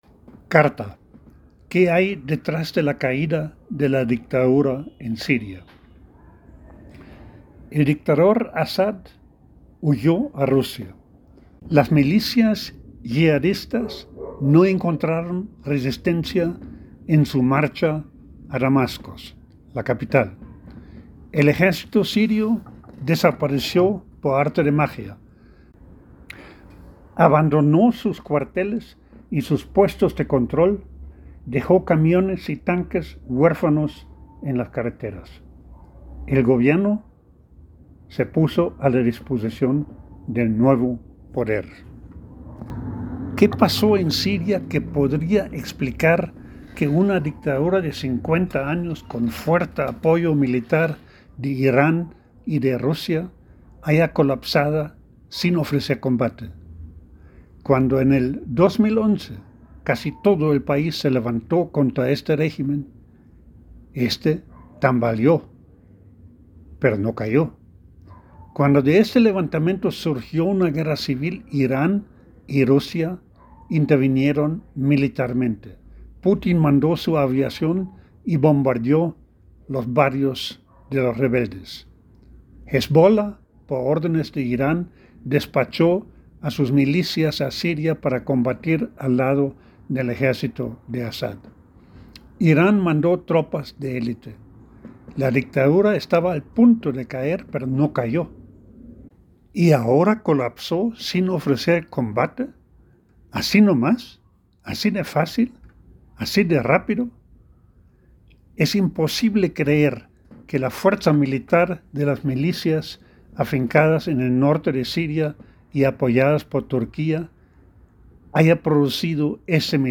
El audio en la voz del autor: